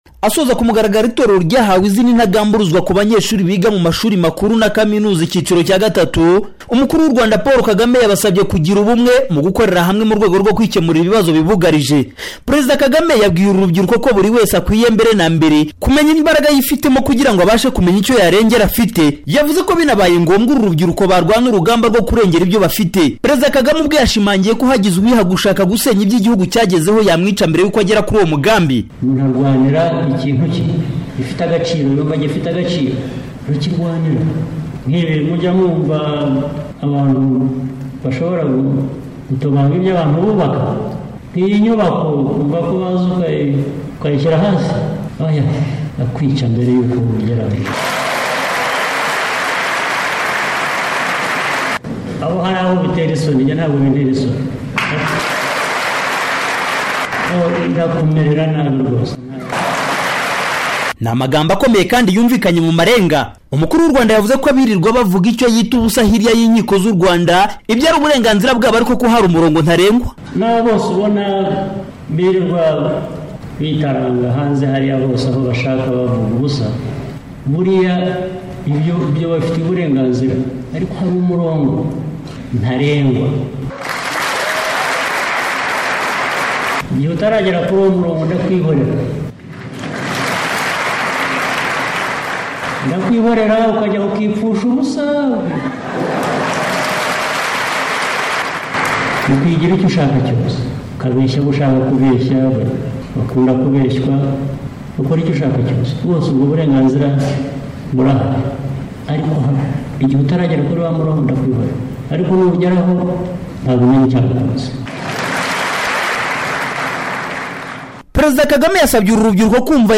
Perezida Paul Kagame mu muhango wo gusoza itorero ry'abanyeshuli biga mu mashuli makuru na za Kaminuza mu Rwanda